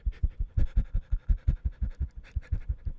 1 channel
pant2.mp3